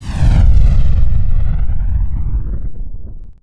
dull roar.wav